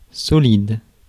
Ääntäminen
France: IPA: [sɔ.lid]